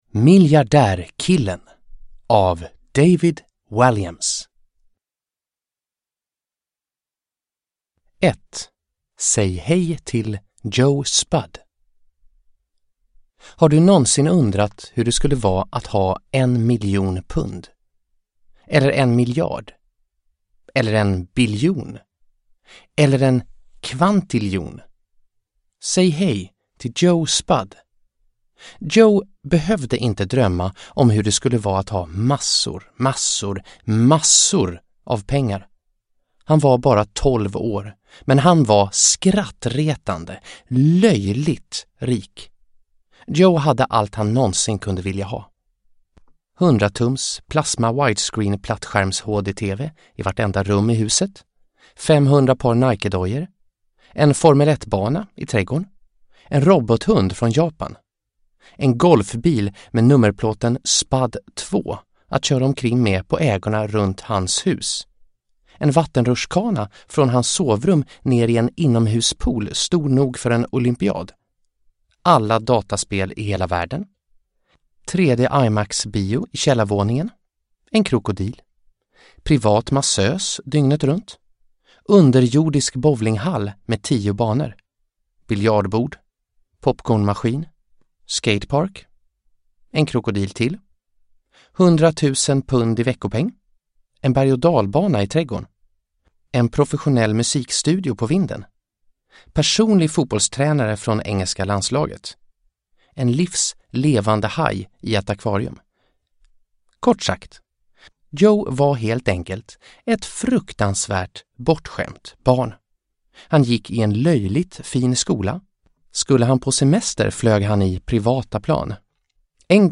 Miljardärkillen – Ljudbok – Laddas ner